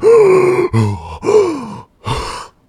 breath01.ogg